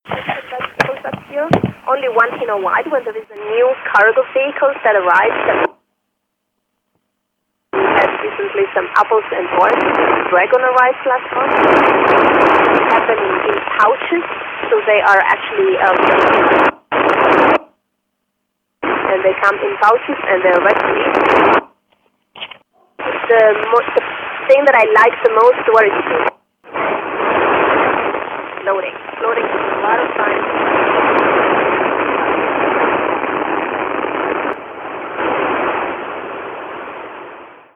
Voice over Romania
Astronaut Samantha Cristoforetti talking probably with students.
ISS voice Samantha Cristoforetti 16bit.mp3